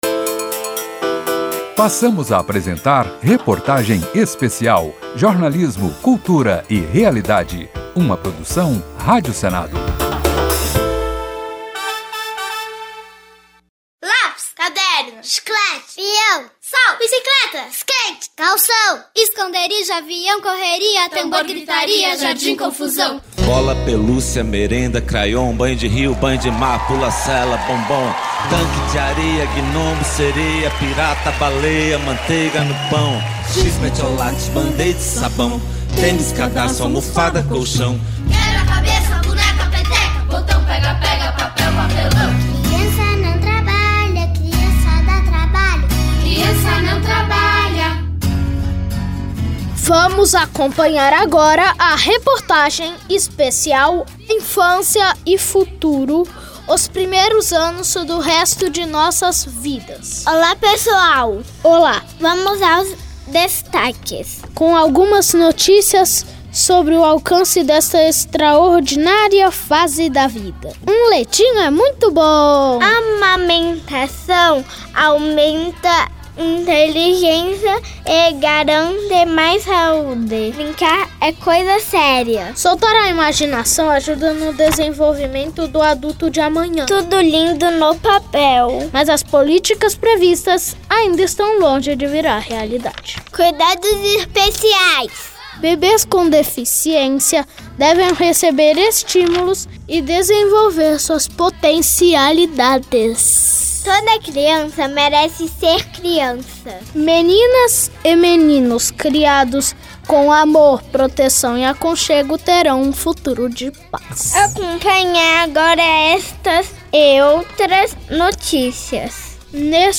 Rádio Senado apresenta reportagem especial sobre a primeira infância
Os depoimentos de médicos, pedagogos, enfermeiros, fisioterapeutas, mães e crianças reforçam que a criação de vínculos afetivos seguros com os pais nesta fase da vida ajudam a criar adolescentes e adultos com maior autonomia e independência para experimentar os desafios da vida.